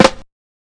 SNARE CLASH.wav